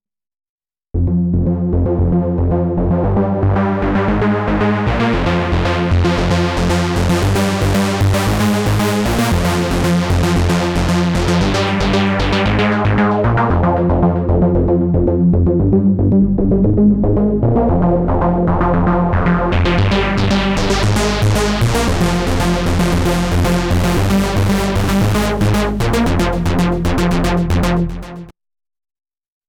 Until then, you can have a lot of fun with Syntakt’s super saw (Swarm), btw. :wink: [Edit: in hindsight, that has more EBM vibes, the second one is bettah]
Or detune two of the six analog saws …